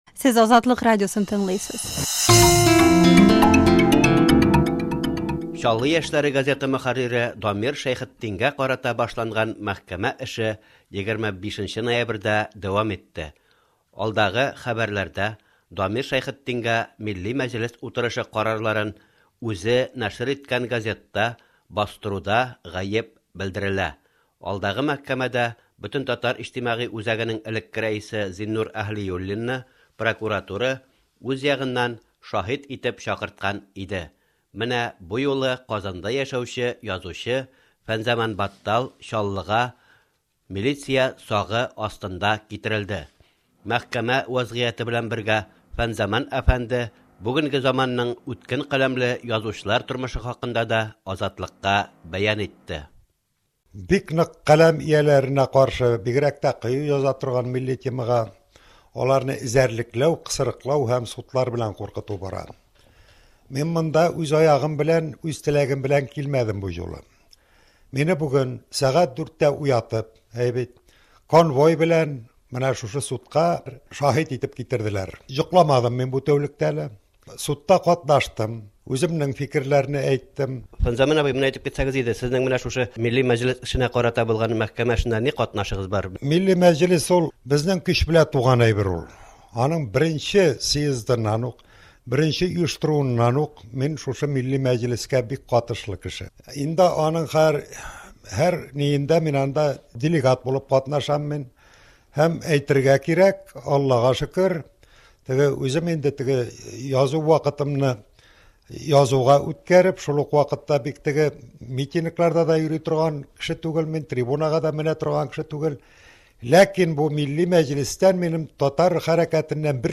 әңгәмә